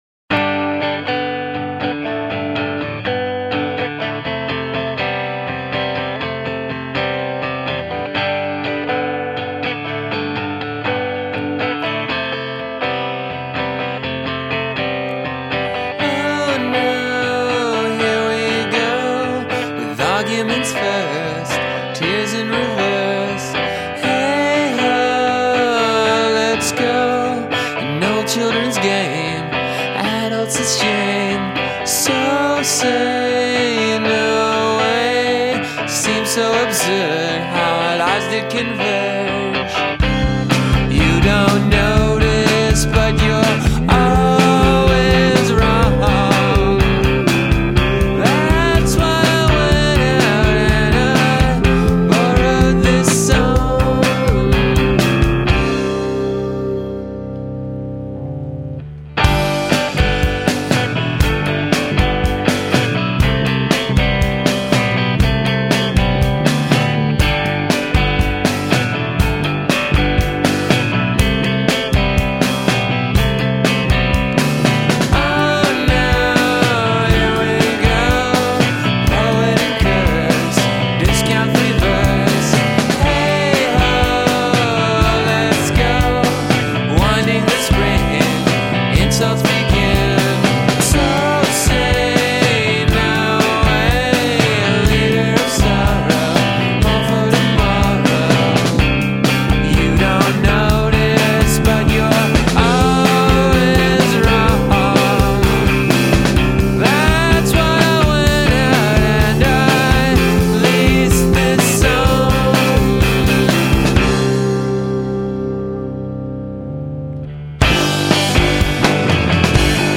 drums, moog and vocals
guitar and vocals
bass and expletives